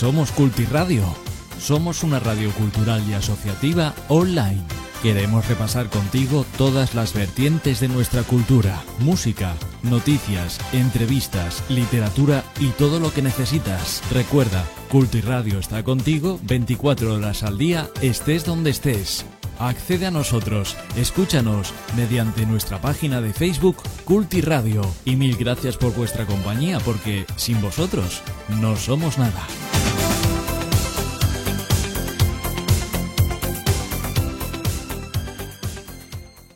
Identificació de la ràdio